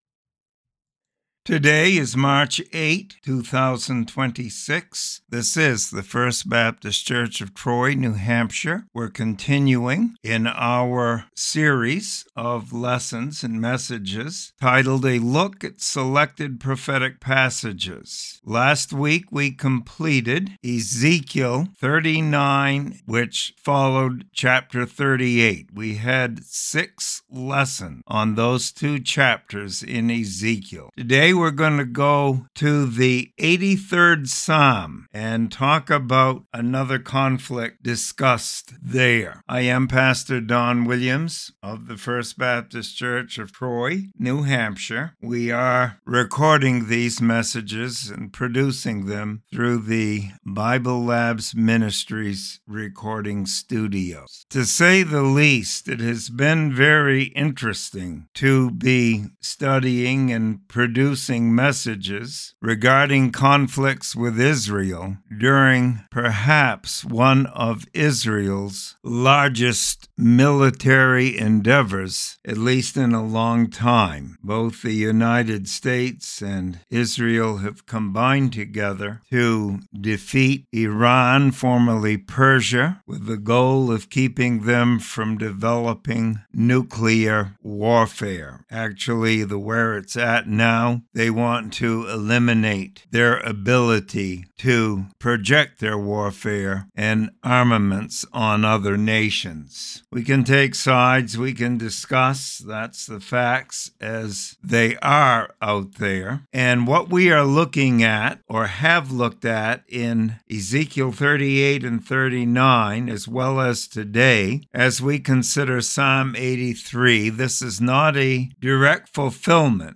Bible study and commentary on Psalm 83.